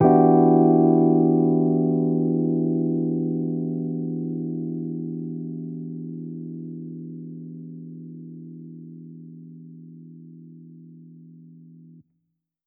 JK_ElPiano2_Chord-Em9.wav